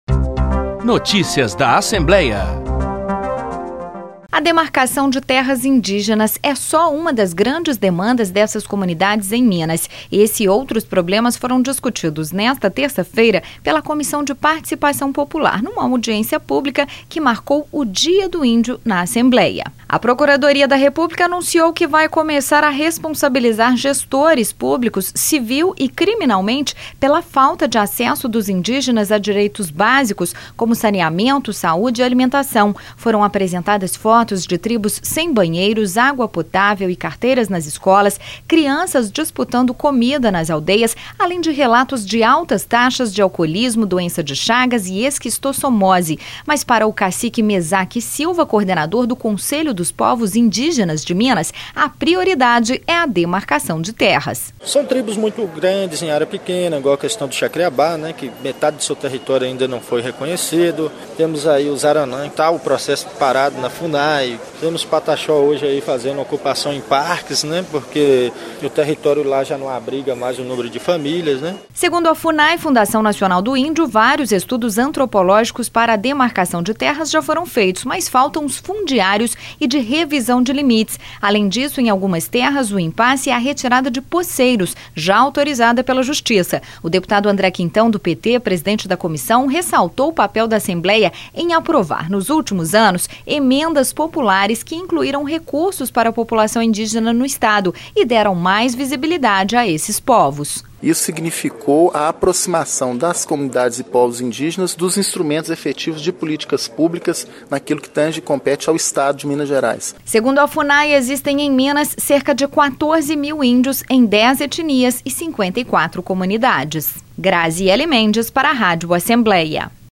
Em audiência pública da Comissão de Participação Popular, que marcou o Dia do Índio, representantes de comunidades indígenas reclamaram de problemas como impasses na demarcação de terras e falta de acesso a saneamento básico, saúde e alimentação.